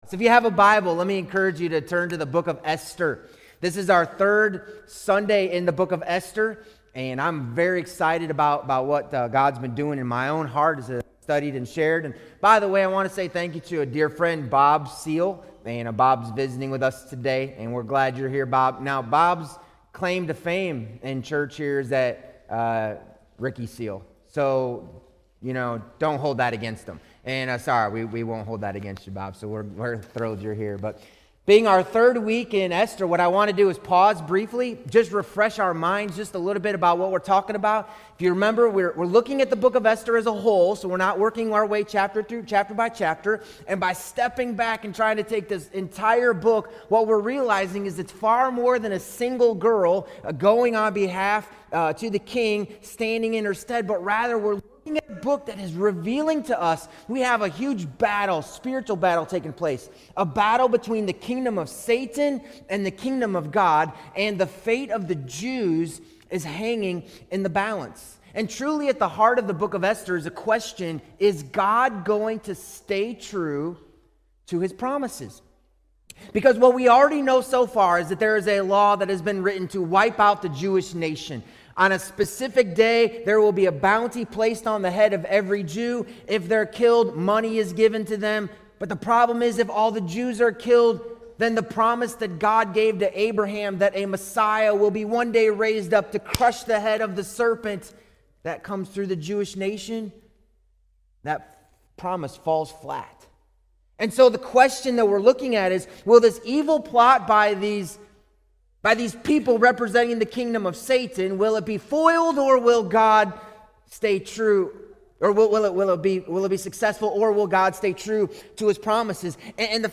Sermons | Mt. Carmel Regular Baptist Church